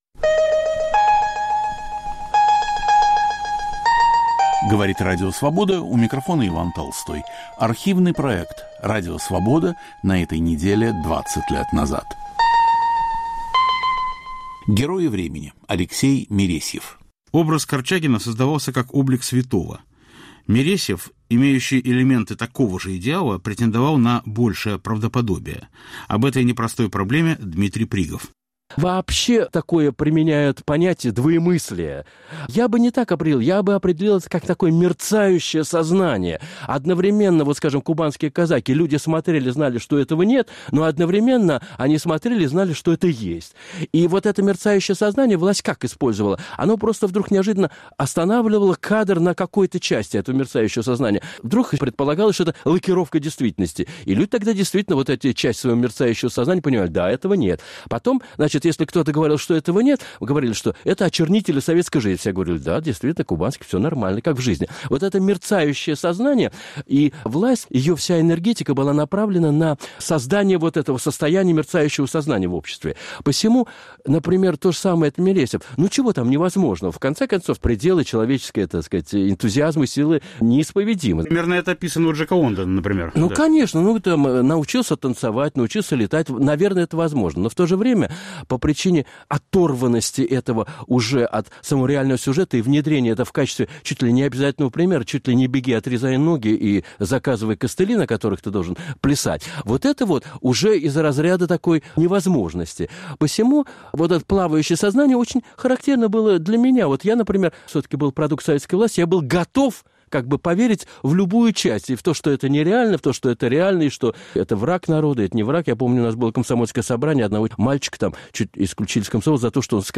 Автор и ведущий Петр Вайль.